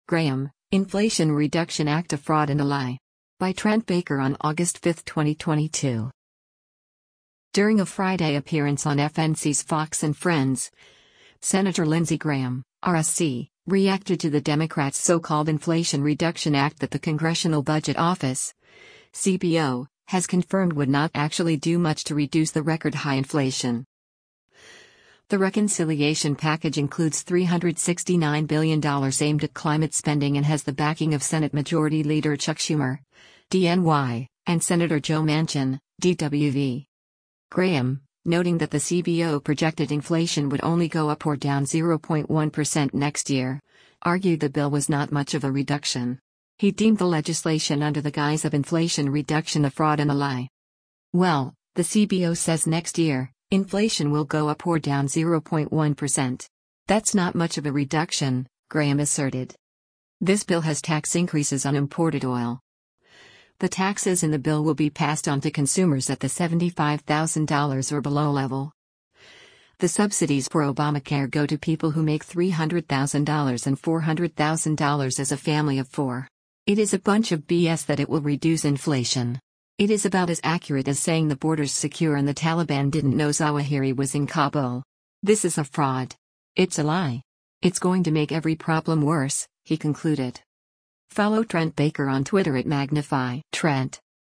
During a Friday appearance on FNC’s “Fox & Friends,” Sen. Lindsey Graham (R-SC) reacted to the Democrats’ so-called “Inflation Reduction Act” that the Congressional Budget Office (CBO) has confirmed would not actually do much to reduce the record-high inflation.